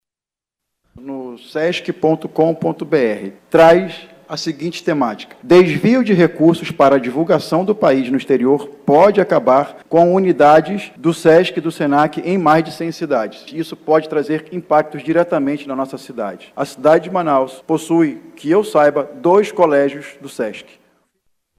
Em Manaus, o vereador Peixoto ressalta os prejuízos para a capital amazonense.
Sonora-Peixoto-vereador.mp3